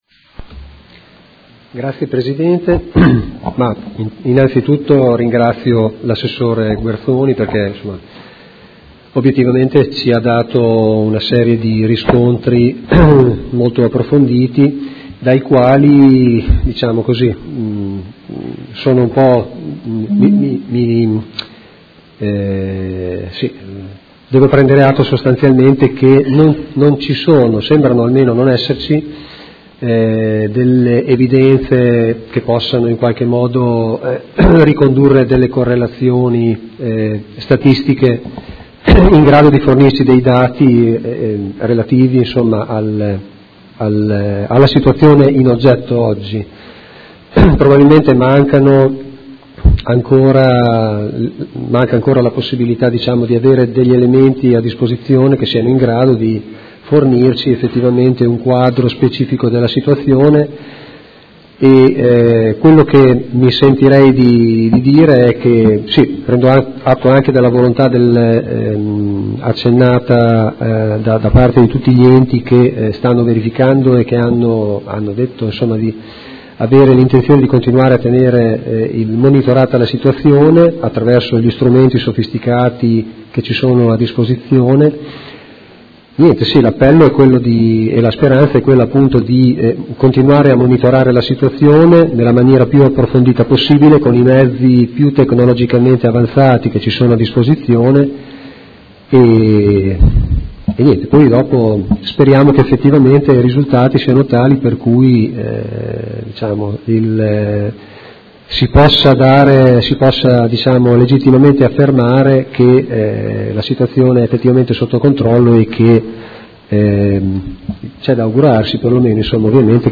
Seduta del 18/05/2017. Conclude interrogazione dei Consiglieri Malferrari e Trande (Art.1-MDP) avente per oggetto: Preoccupazione dei residenti che vivono intorno alle Fonderie Cooperative Riunite: ci sono dati epidemiologici che evidenzino una maggiore morbilità o mortalità nell’area?
Audio Consiglio Comunale